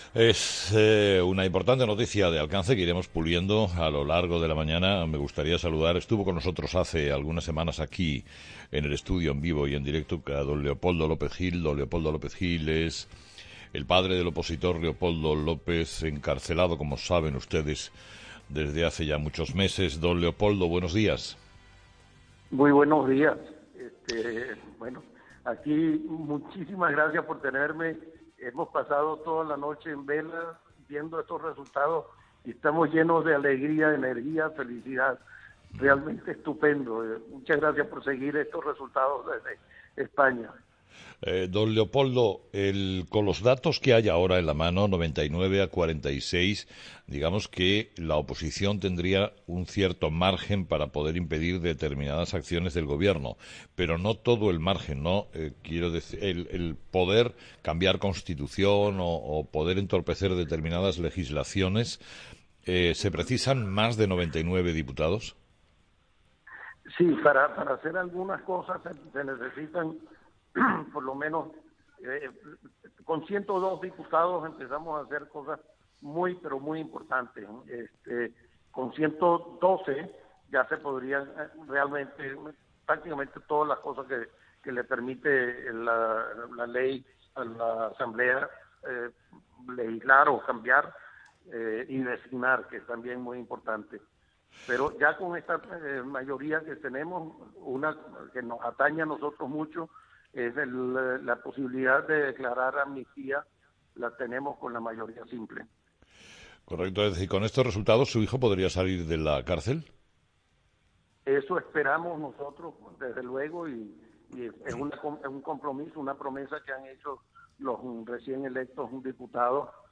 Leopoldo López Gil, padre de Leopoldo López, en Herrera en  COPE